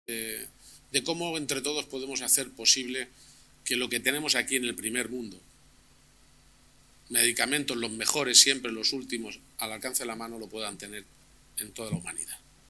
Presidente Miércoles, 4 Junio 2025 - 1:30pm En el marco del acto de celebración del 40 aniversario de la farmacéutica Johnson & Jonhson en Toledo, el presidente regional ha expresado la necesidad de que el acceso a los medicamentos no esté restringido al primer mundo y que llegue también a toda la humanidad. garciapage_medicamentos_toda_la_humanidad.mp3 Descargar: Descargar